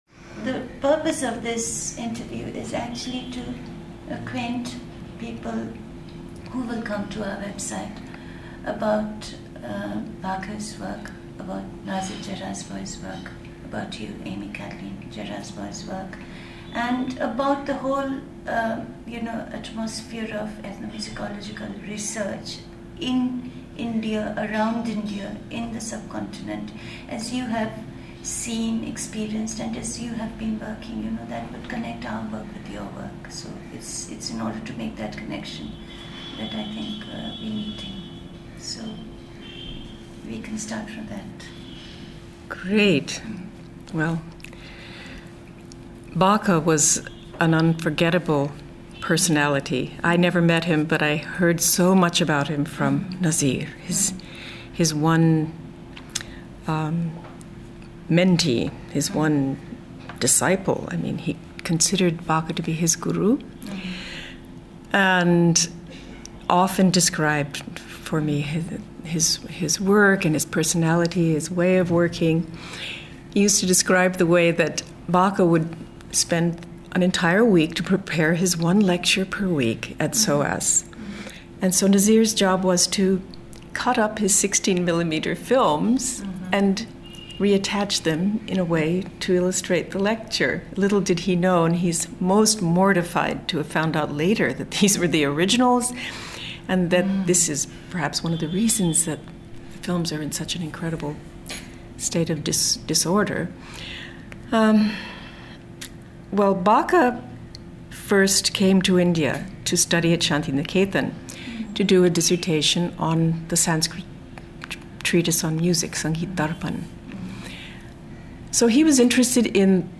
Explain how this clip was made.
Here are extracts from their conversation.